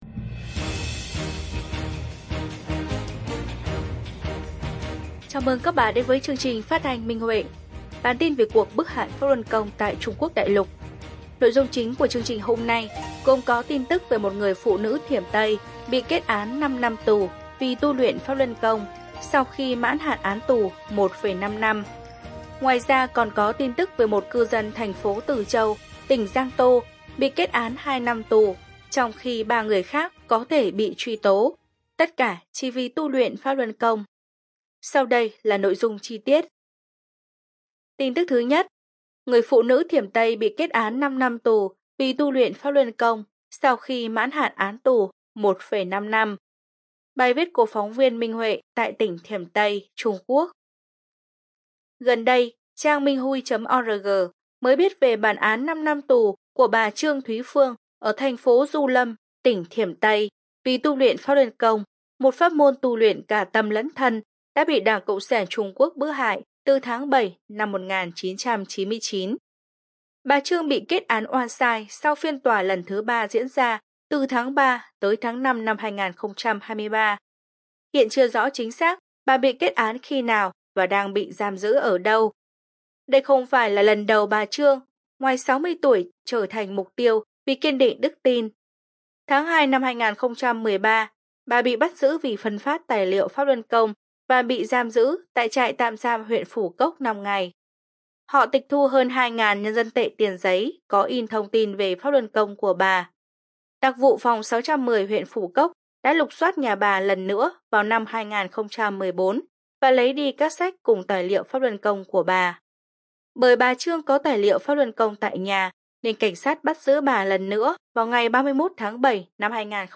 Chúng tôi muốn gửi đến các bạn tin tức về cuộc bức hại Pháp Luân Công tại Trung Quốc cũng như những câu chuyện chia sẻ tâm đắc thể hội của các học viên Pháp Luân Công trong quá trình tu luyện, cùng âm nhạc do chính các học viên sáng tác và trình bày.